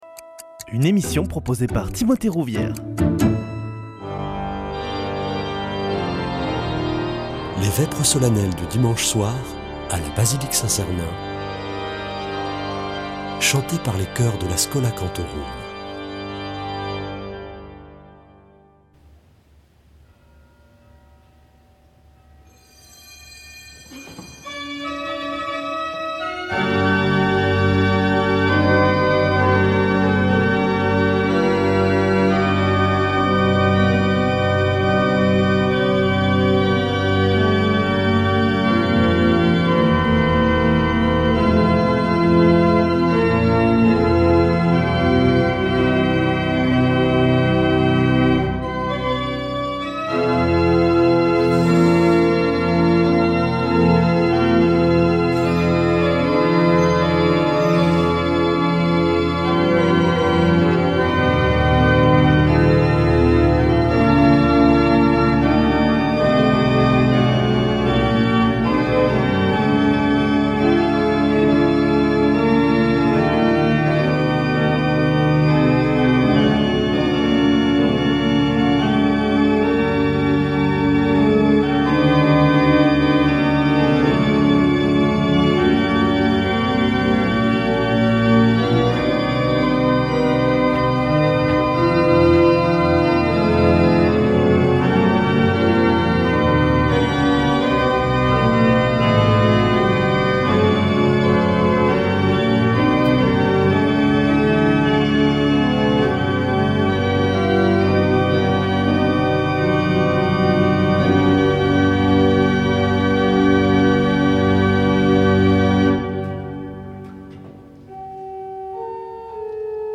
Vêpres de Saint Sernin du 21 janv.
Une émission présentée par Schola Saint Sernin Chanteurs